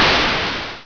playerhit.wav